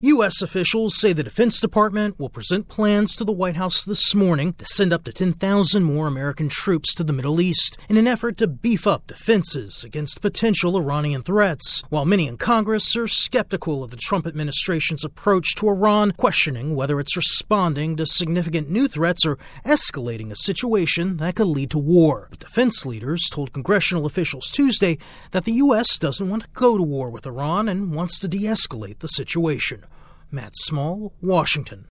The Pentagon is drawing up plans for a large troop buildup in the Middle East. AP correspondent